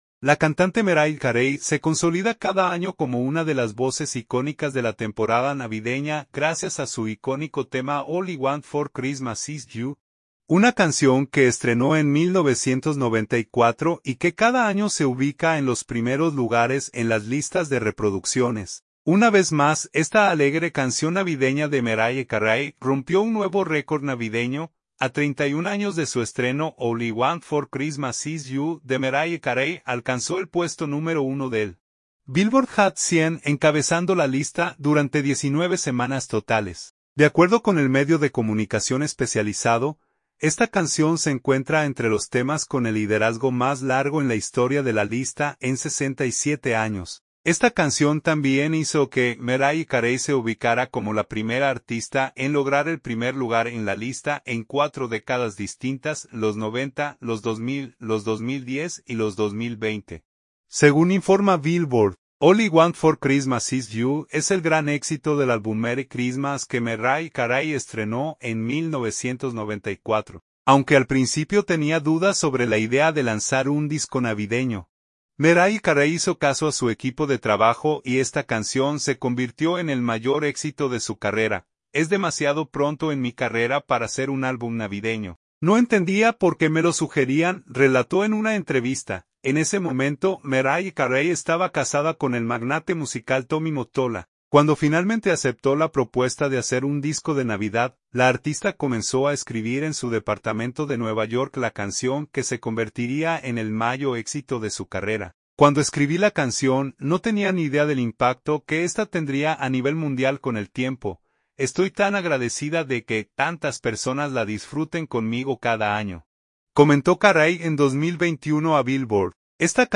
alegre canción navideña